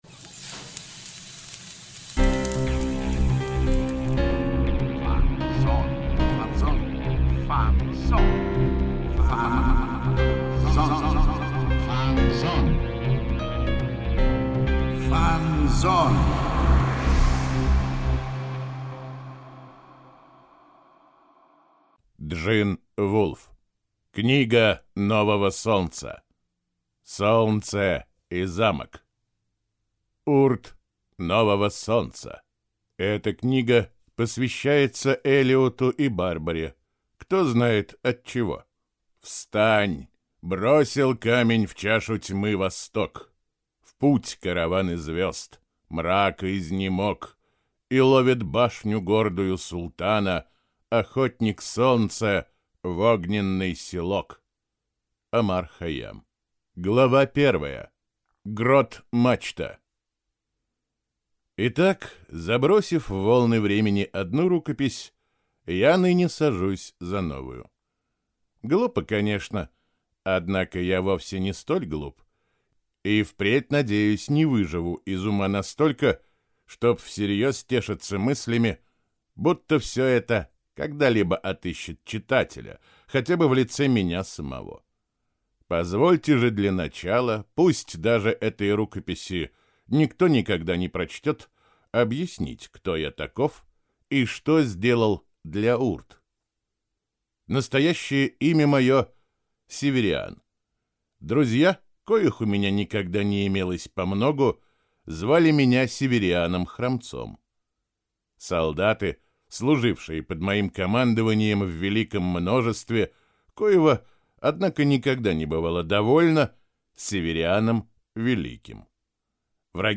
Аудиокнига Солнце и Замок | Библиотека аудиокниг